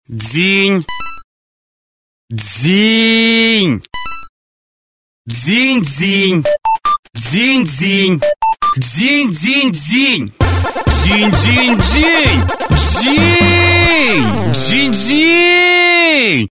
» Буд - дзин-дзин Размер: 132 кб